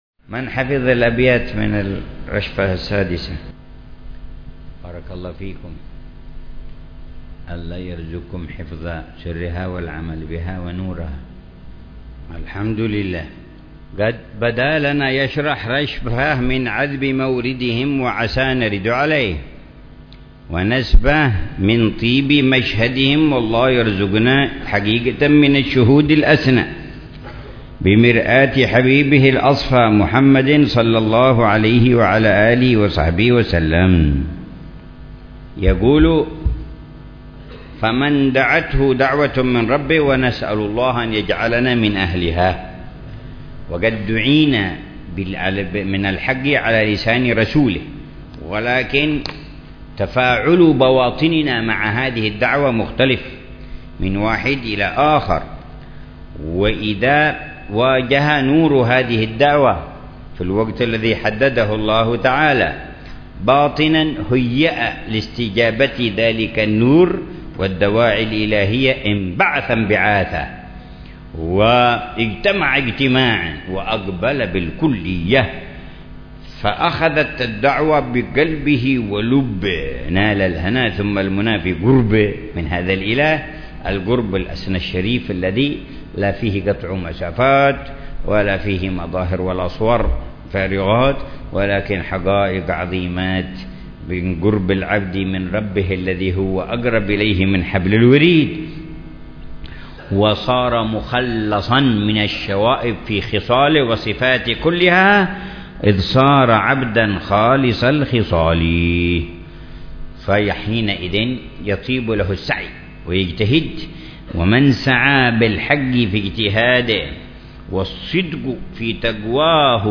شرح الحبيب عمر بن محمد بن حفيظ لرشفات أهل الكمال ونسمات أهل الوصال.